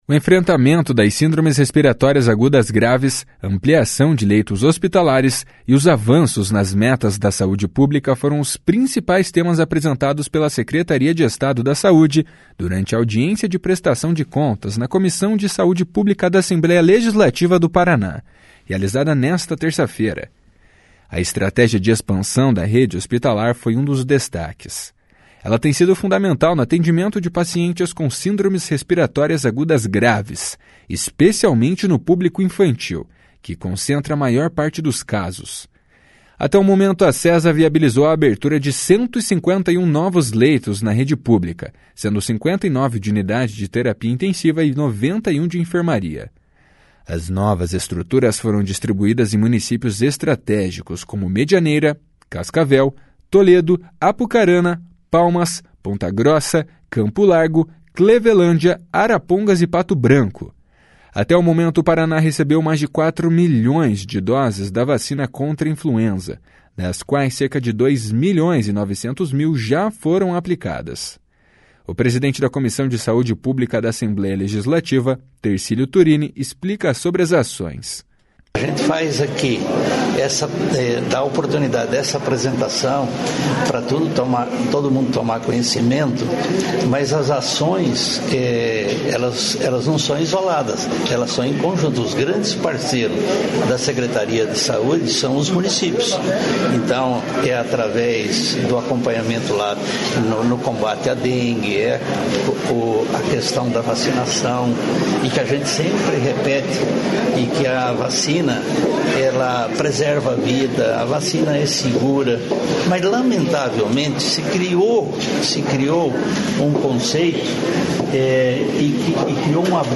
O presidente da Comissão de Saúde Pública da Assembleia Legislativa, Tercílio Turini, explica sobre as ações. // SONORA TERCÍLIO TURINI //